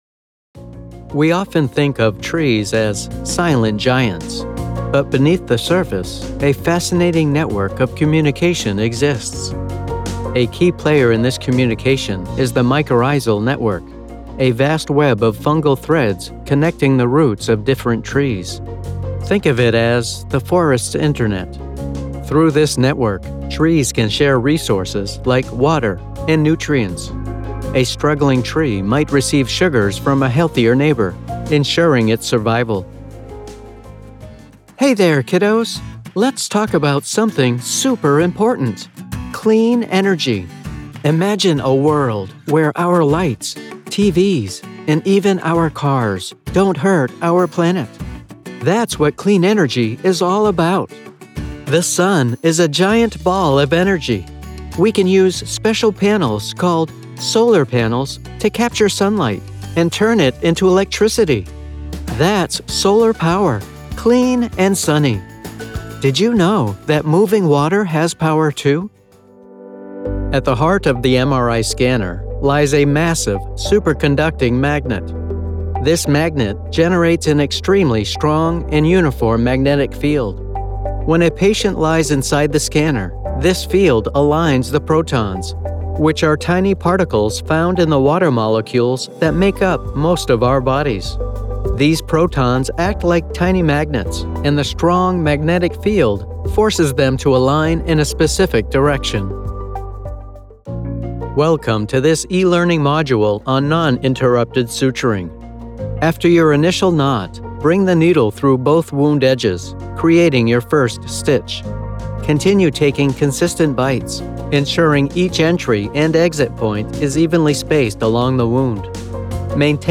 Male
I have a conversational tone that is natural, believable, and friendly.
E-Learning
Elearning Samples